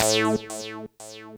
synTTE55012shortsyn-A.wav